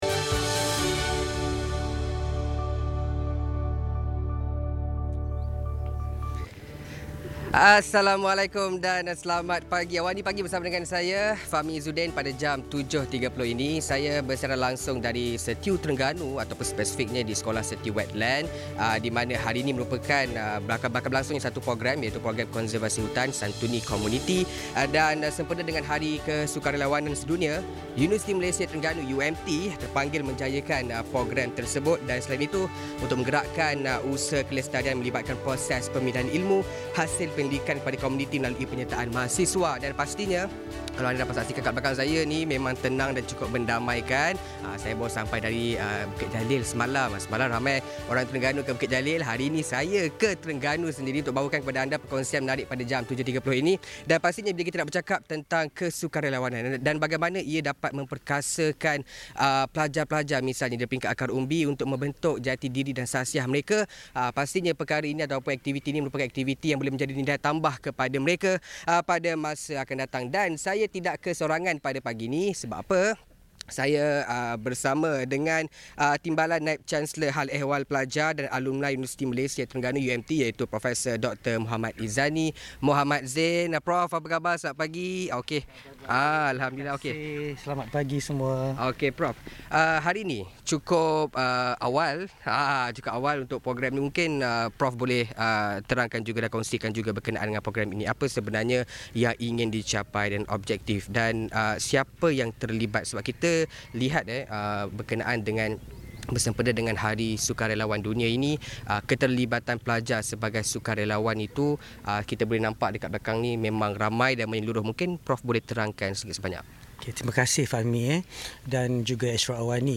Bersiaran langsung dari luar di Hutan Gelam, Penarik, Kuala Terengganu